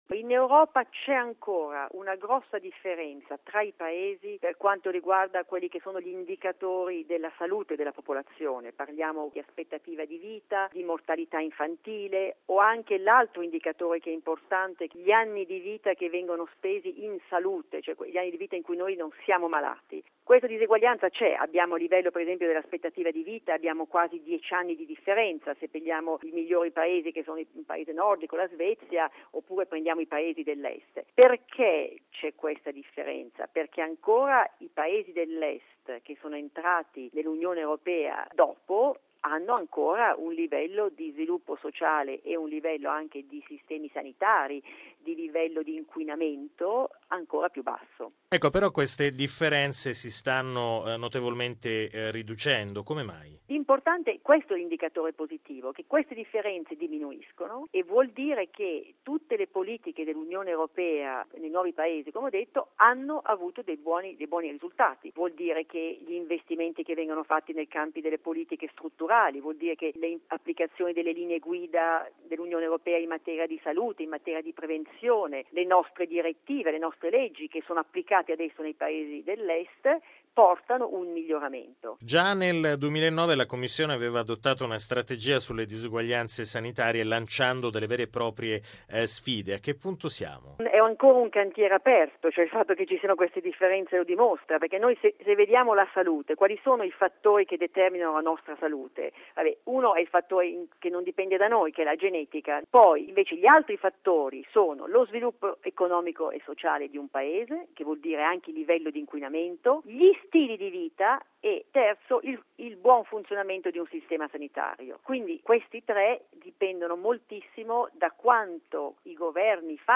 Radiogiornale del 10/09/2013 - Radio Vaticana